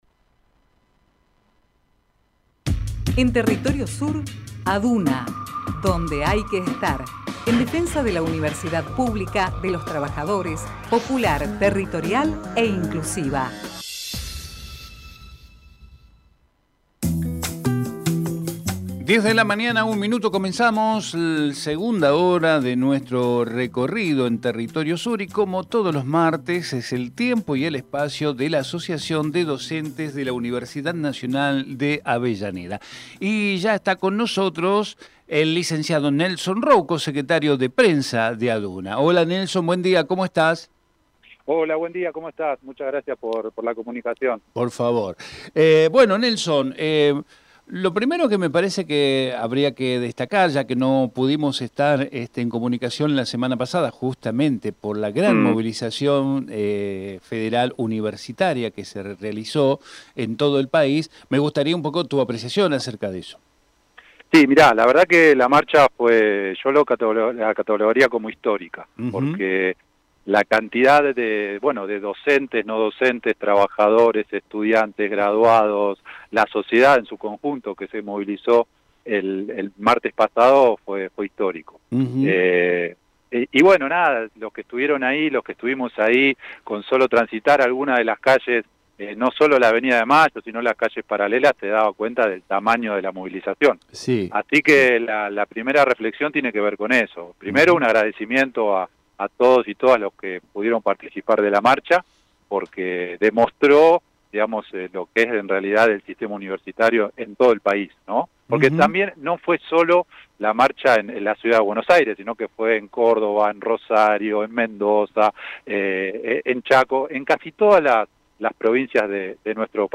Compartimos con ustedes la entrevista